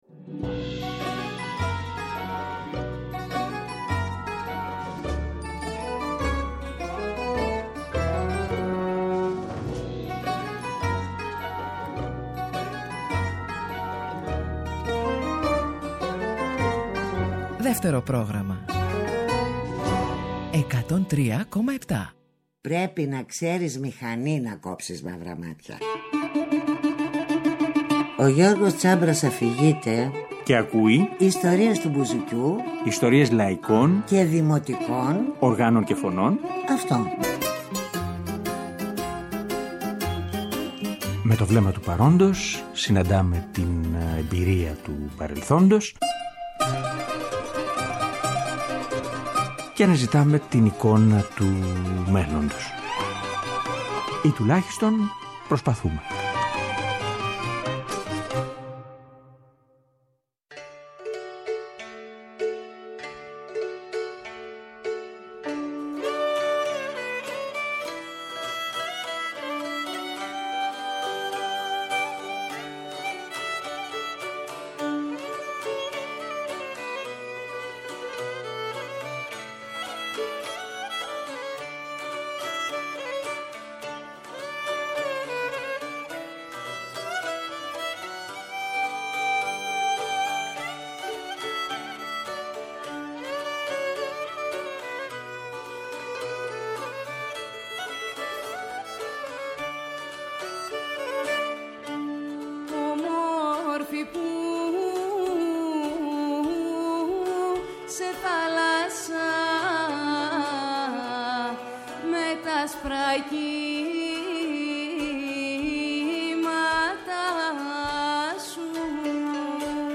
40 χρόνια -τραγούδια, σε μια ηχογράφηση του 2013
στο σαντούρι
στο λαούτο